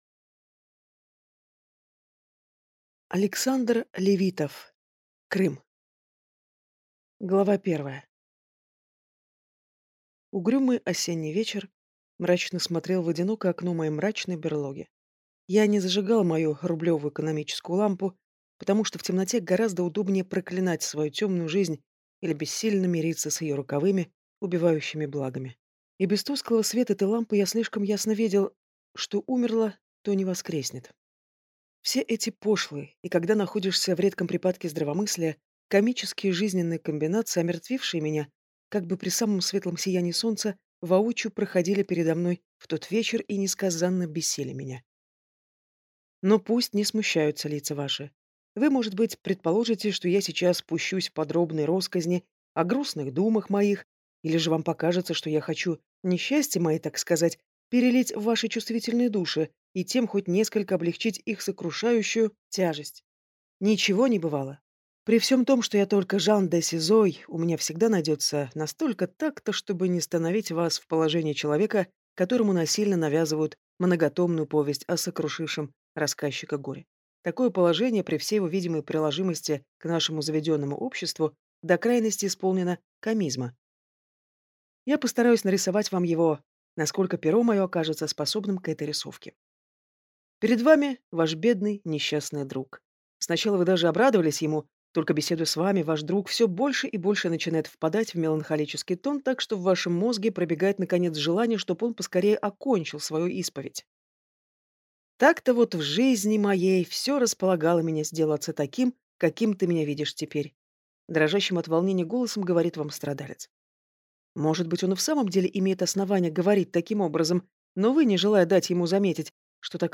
Аудиокнига «Крым» | Библиотека аудиокниг